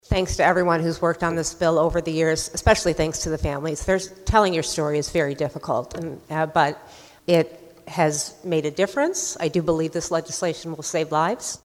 REPUBLICAN REPRESENTATIVE ANN MEYER OF FORT DODGE LED HOUSE DEBATE WEDNESDAY.